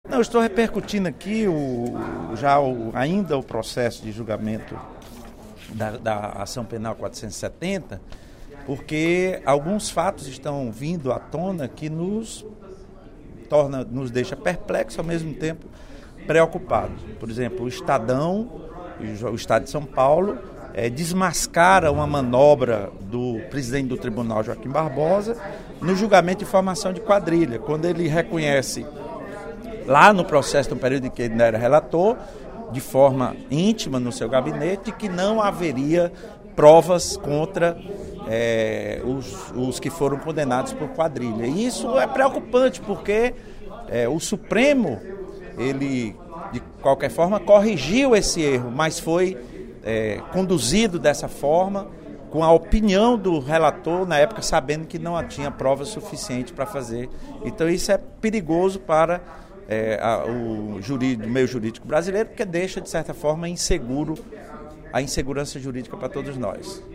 Em pronunciamento no primeiro expediente da sessão plenária desta sexta-feira (07/03), o deputado Lula Morais (PCdoB) ressaltou que o presidente do Supremo Tribunal Federal (STF), ministro Joaquim Barbosa, não tinha provas contra o principal condenado no processo do mensalão, o ex-ministro José Dirceu.